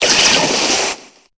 Cri de Triopikeur dans Pokémon Épée et Bouclier.